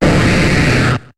Cri de Léviator dans Pokémon HOME.